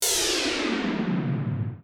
powerout.wav